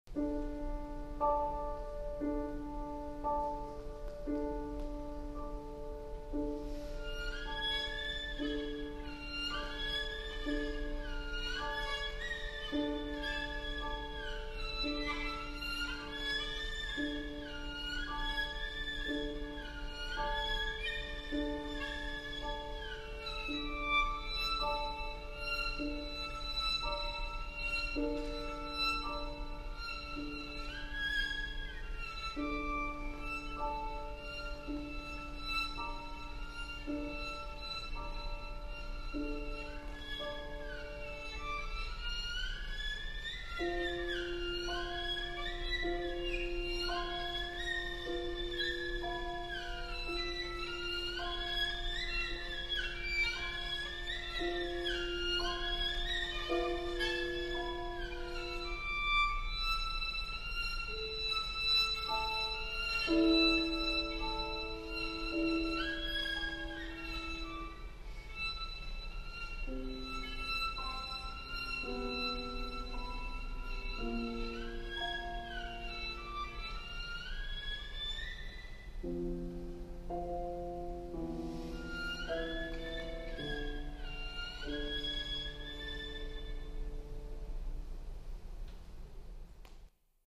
Bela Bartok: Roumanian Folk Dances: Allegro